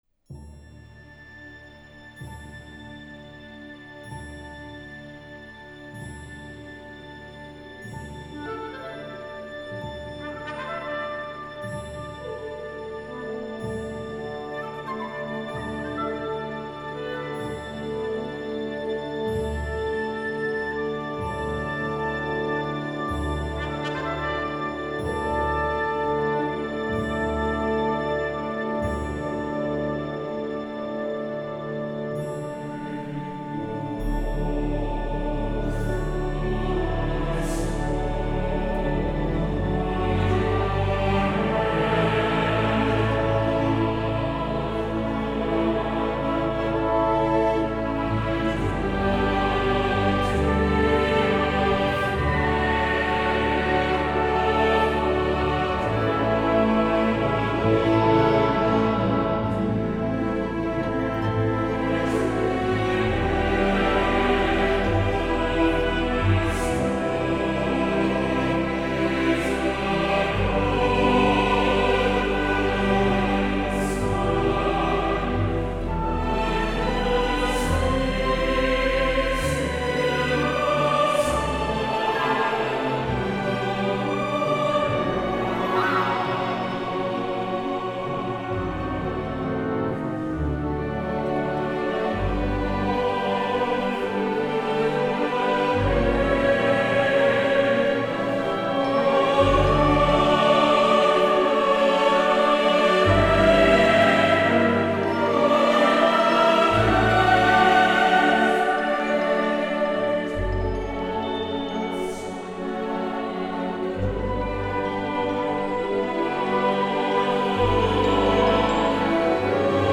recorded in Abbey Road Studios.